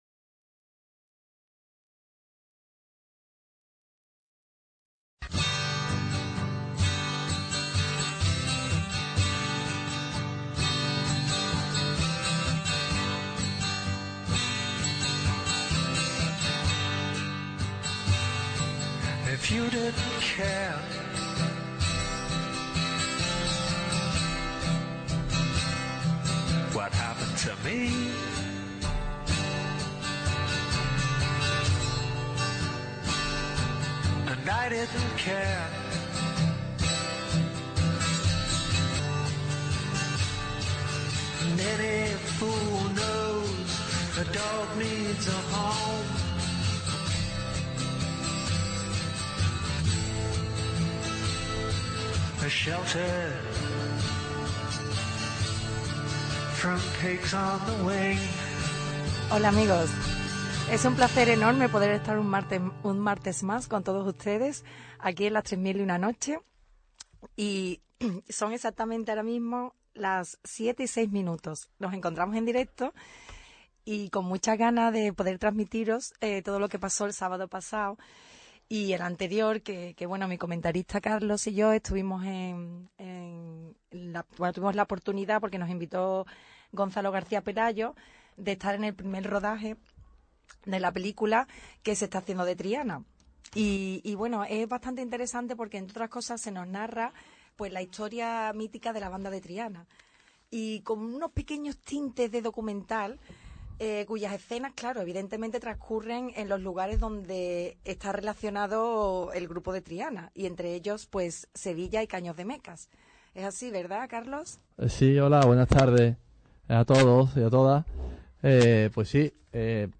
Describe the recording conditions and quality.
El tercer programa de esta gran iniciativa, “LAS TRESMIL Y UNA NOCHE”, reproduce en vivo la atmósfera del rodaje de “Todo es de color”.